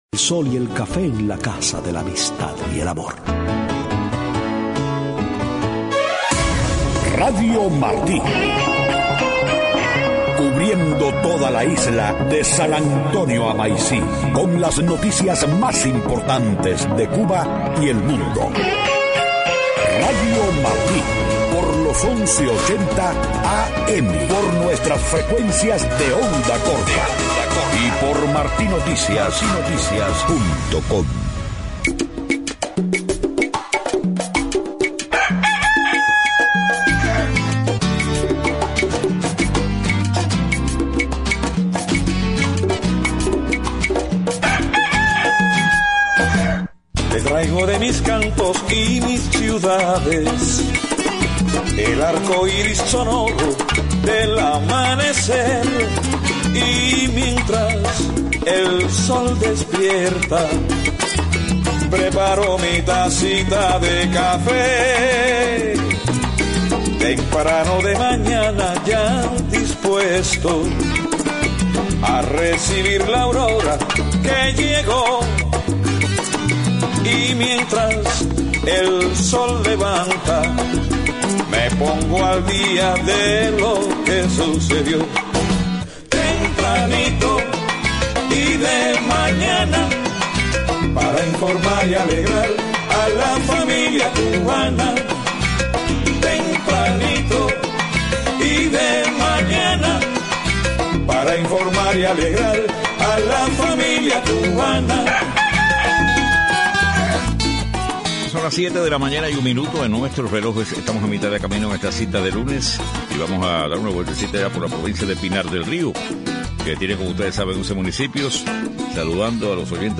7:00 a.m Noticias: Llegó a La Habana el presidente de Francia François Hollande. Hallan muetos en el sur de Argelia a 2 médicos y una enfermera cubanos.